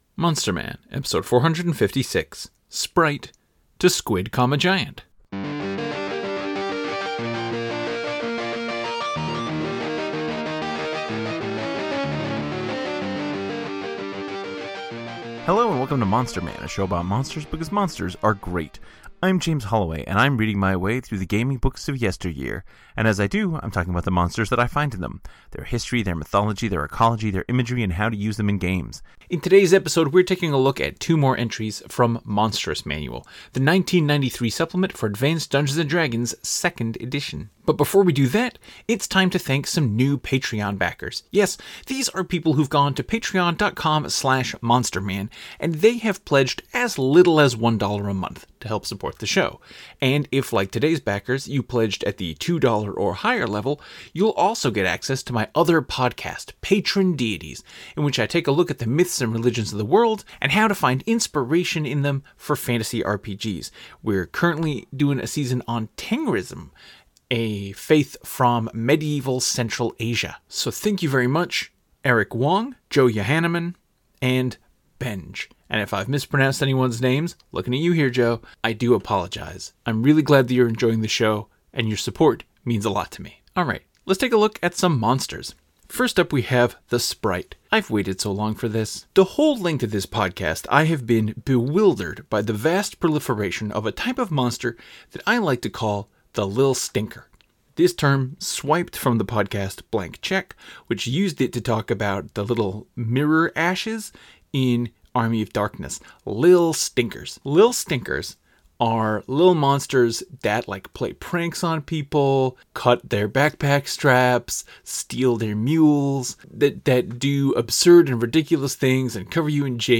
The intro music is a clip